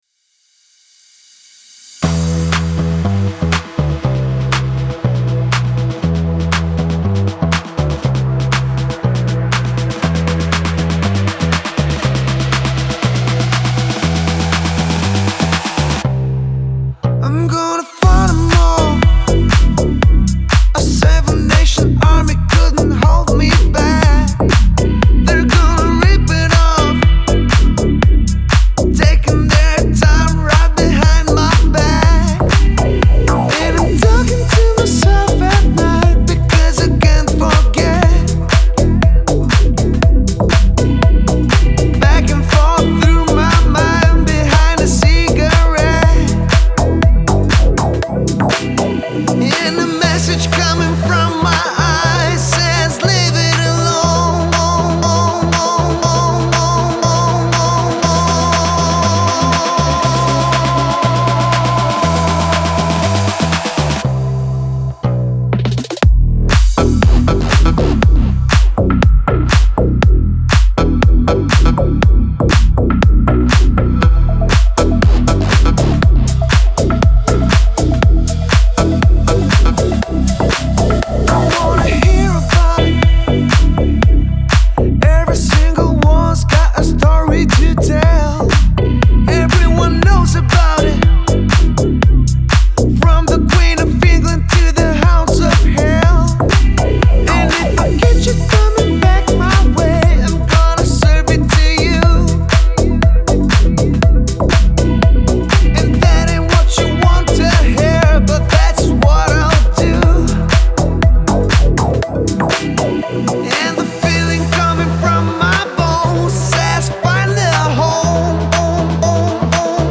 энергичный ремикс